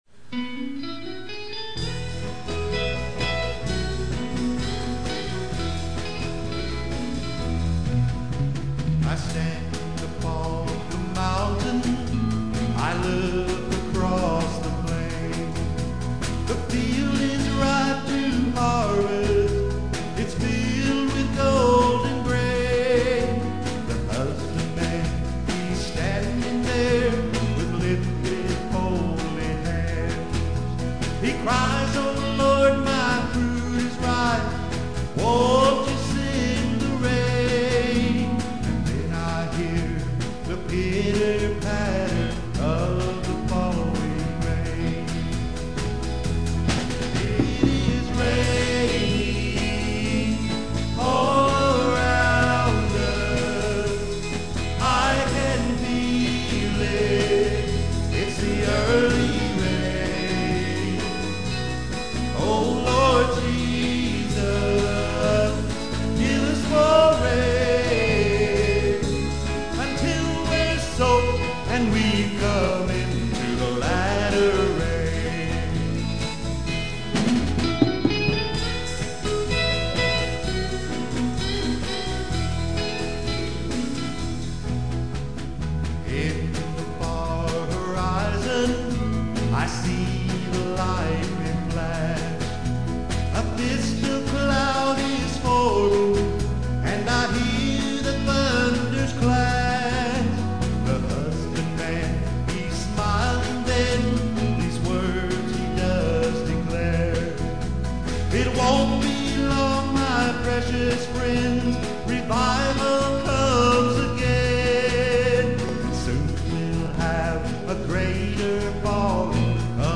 The fellowship sang it a few times just the way I wanted
recorded it in his home studio